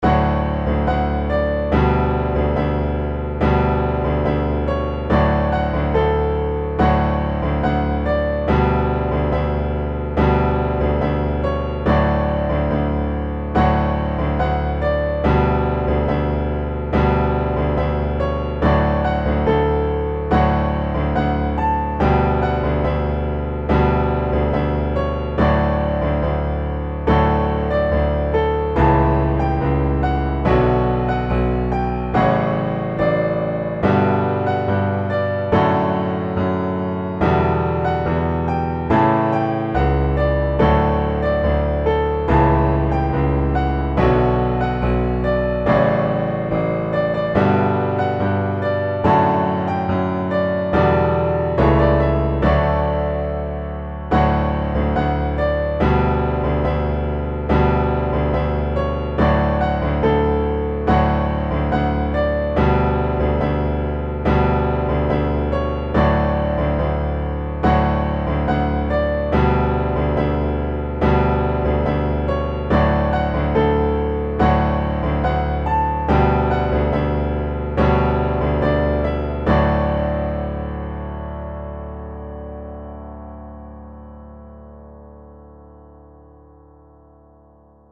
ドラムによるリズムを付ける。
サイン波による後半の追加伴奏を付ける。
メロディのMIDIデータをボーカロイドにぶち込み、歌わせる。歌詞を考えてみたけど、何となく納得できなかったので歌ってるメロディの各音階を歌わせてみた。